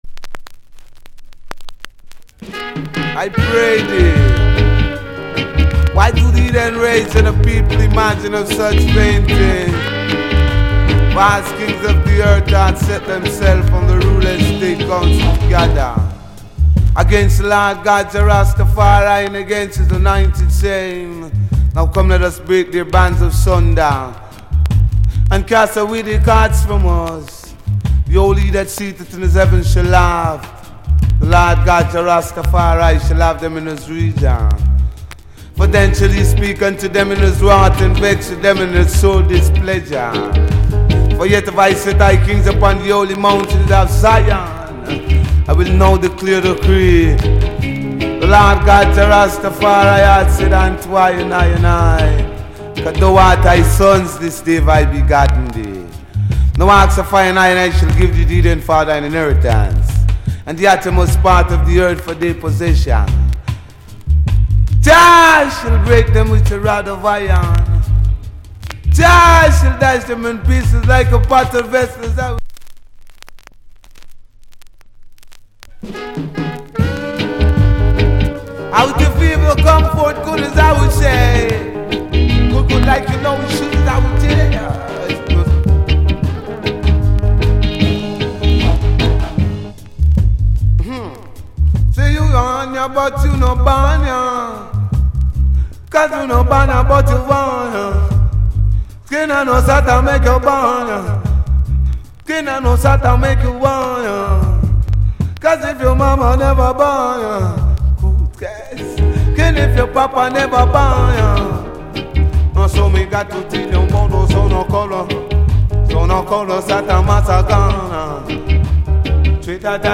DJ Cut. Old Skool.